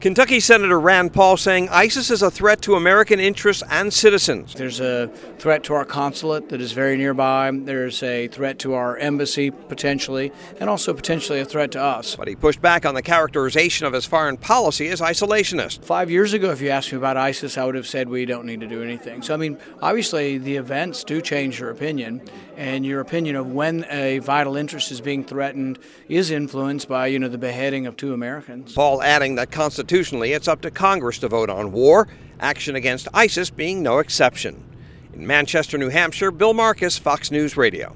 Paul at GOP NH Unity Breakfast